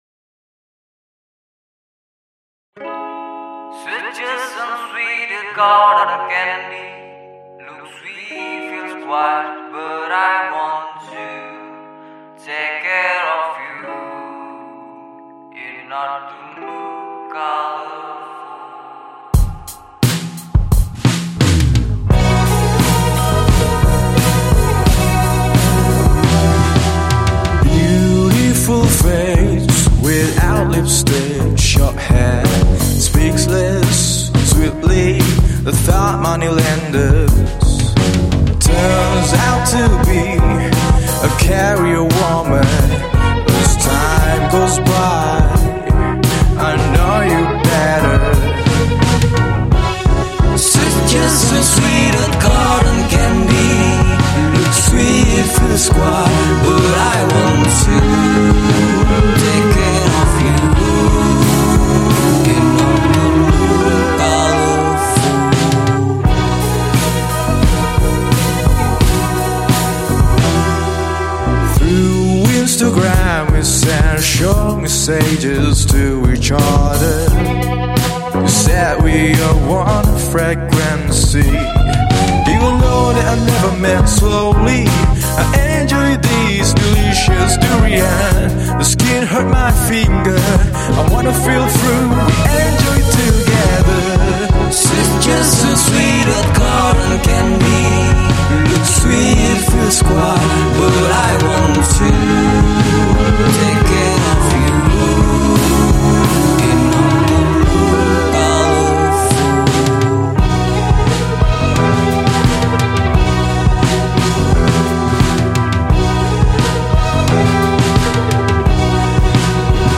Jakarta Alternative